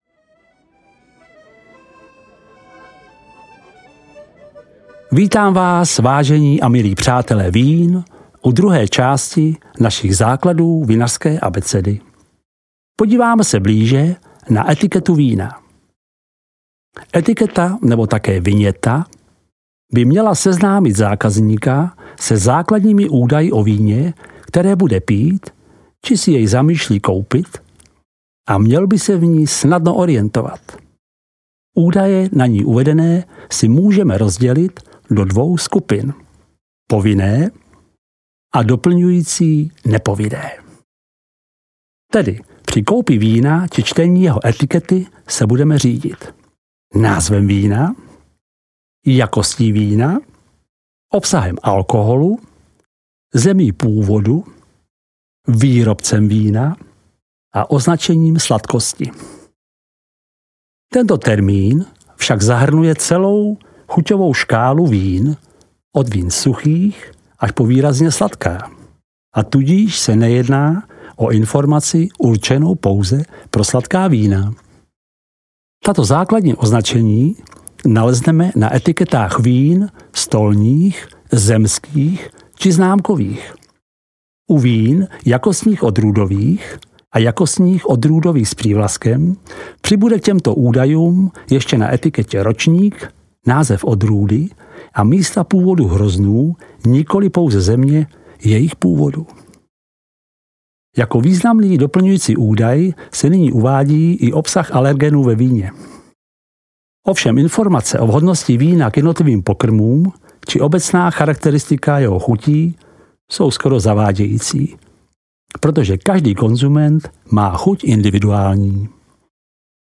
Obdivuhodný svět vín 2 audiokniha
Ukázka z knihy